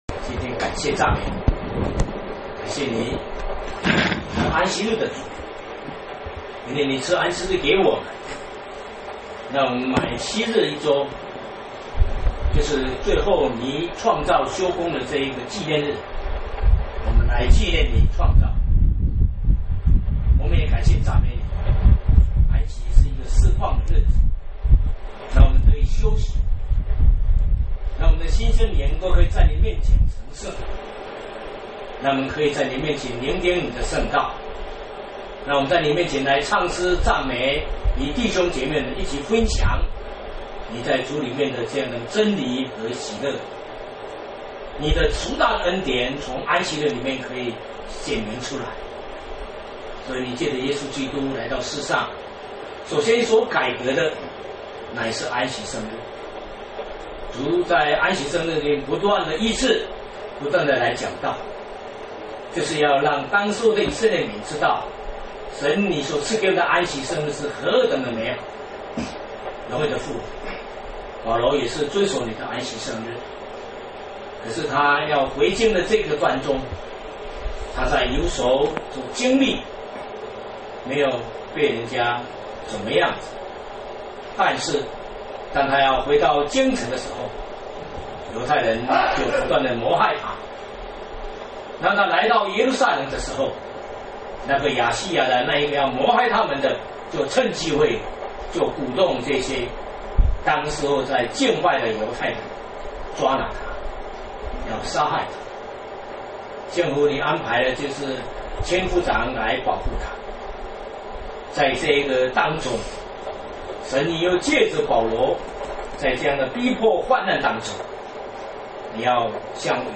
（使徒行傳 22:1-30）-禱告